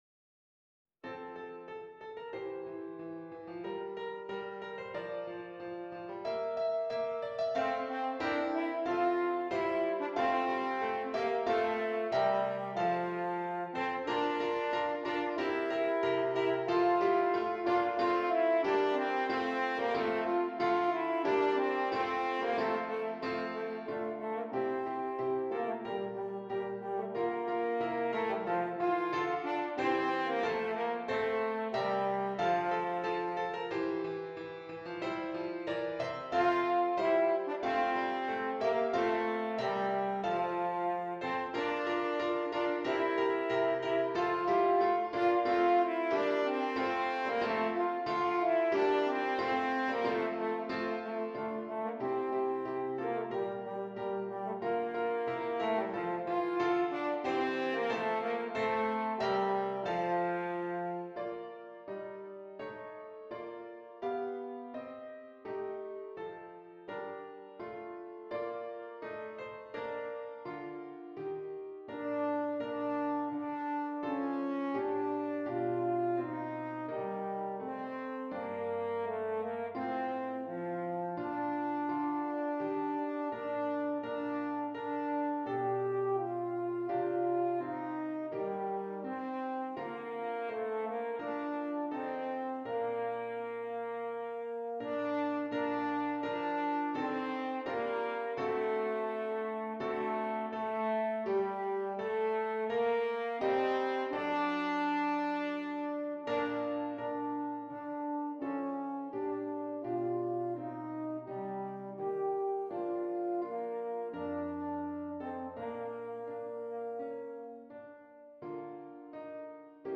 F Horn and Keyboard
Here are 6 Christmas favorites for horn and piano.